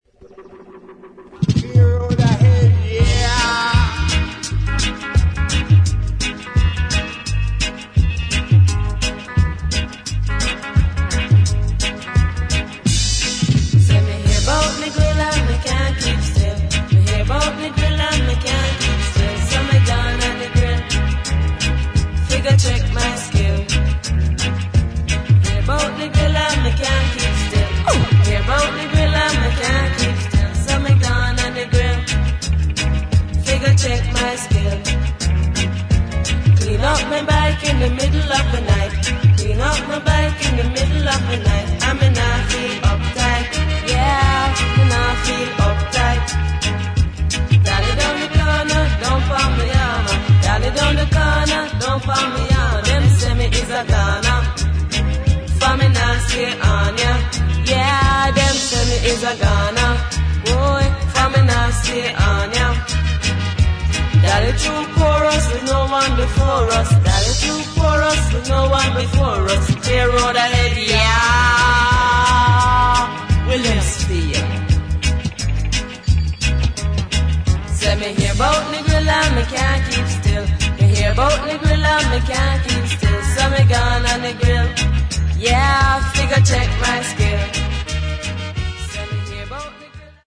[ REGGAE / DUB ]